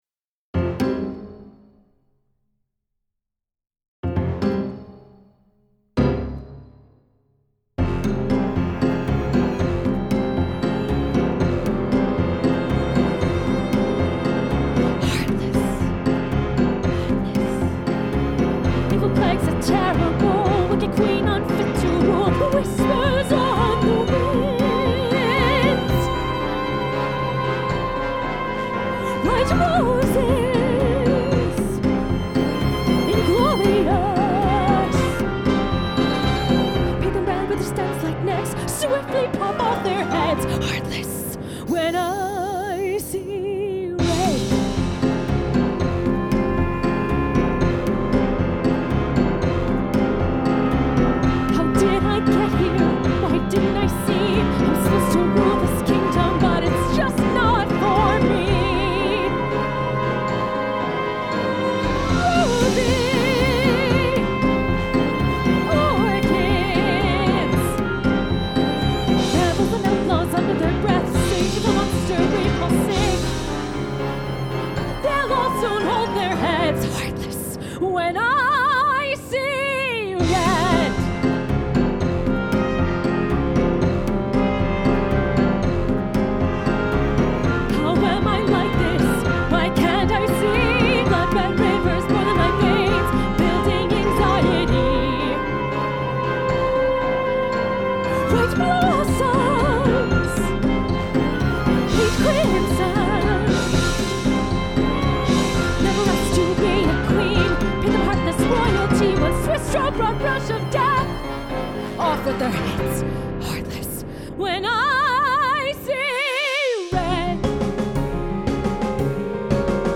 A NEW MUSICAL